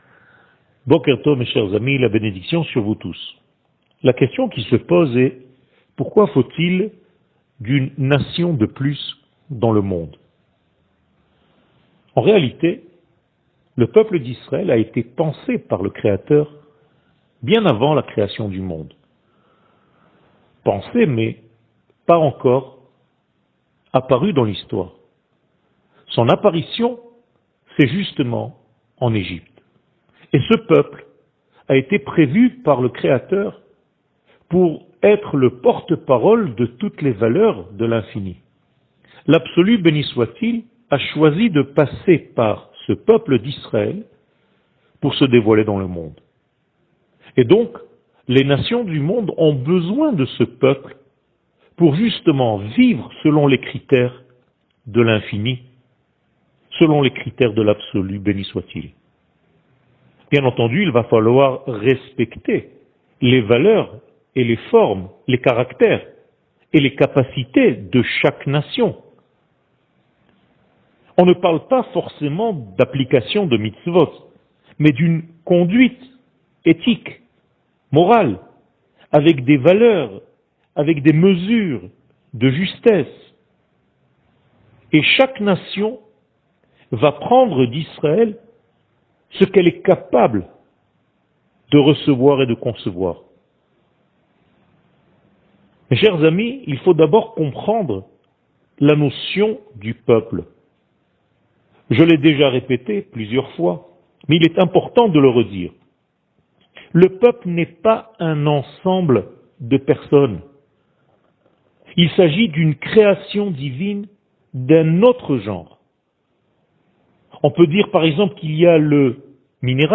שיעור מ 04 ינואר 2022